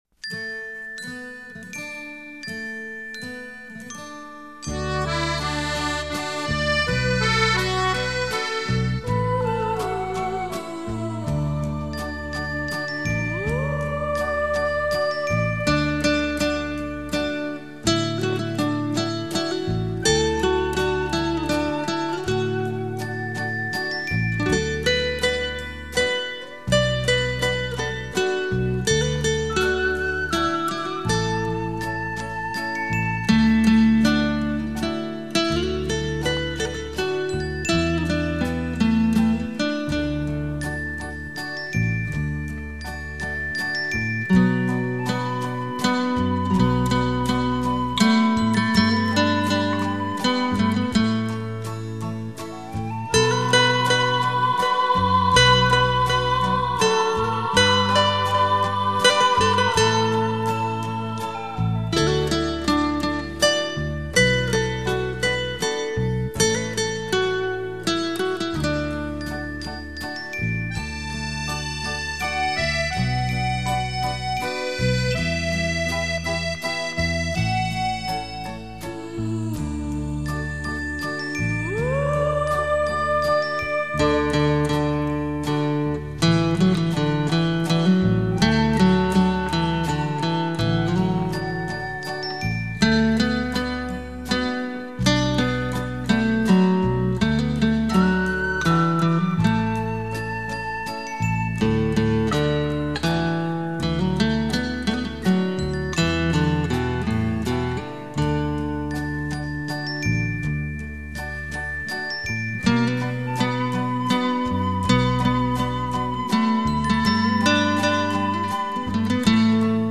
洒出来的音符在您面前组成一幅画